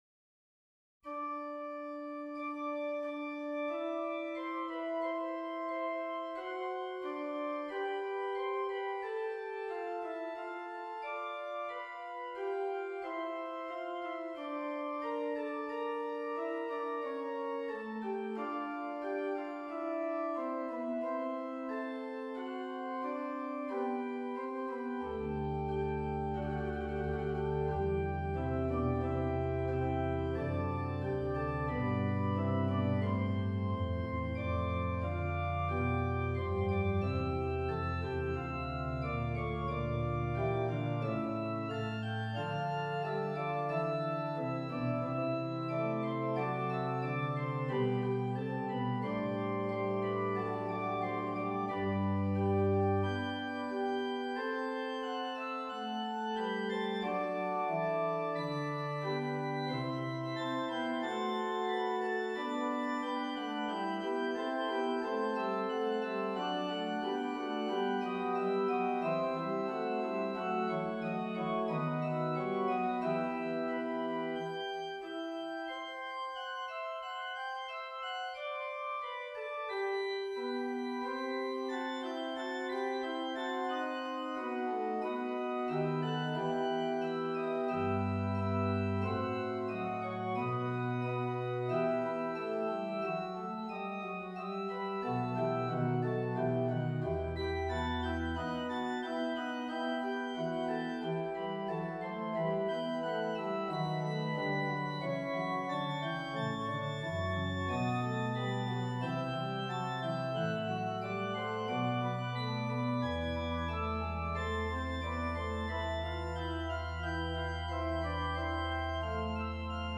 Fantasia per organo pleno in g major.
This week a felt like writing something again, this is a four voiced fantasia for organ written in the "stile antico".